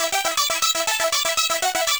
Index of /musicradar/8-bit-bonanza-samples/FM Arp Loops
CS_FMArp B_120-E.wav